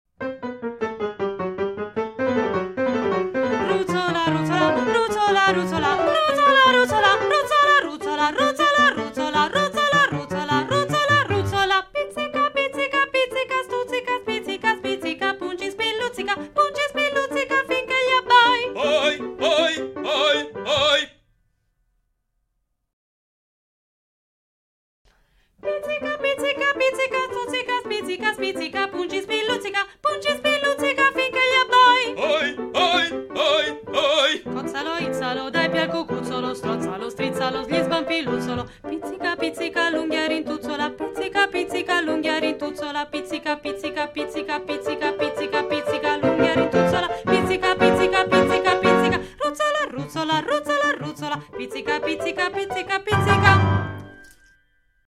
Ruzzola, ruzzola – Ritmica